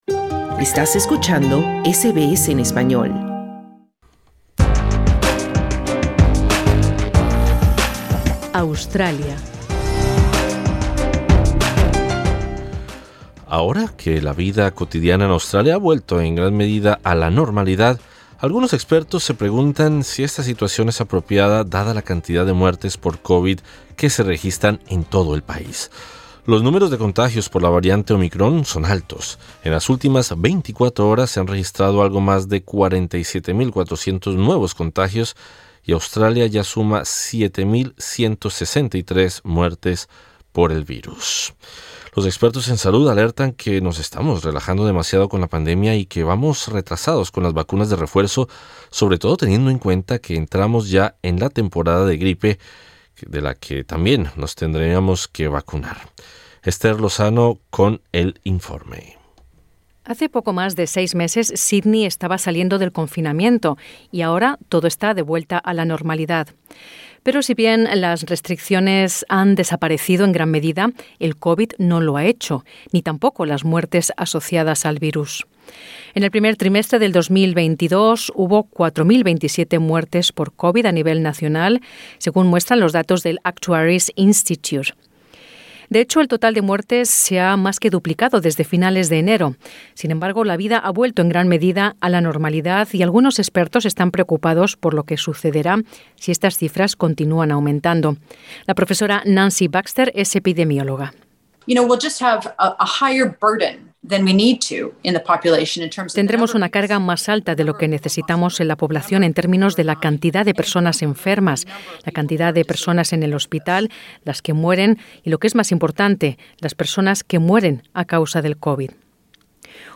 Los expertos en salud alertan que nos estamos relajando demasiado con la pandemia, y que vamos retrasados con las vacunas de refuerzo, sobre todo teniendo en cuenta que entramos ya en la temporada de gripe, de la que también nos tendríamos que vacunar. Escucha el reporte.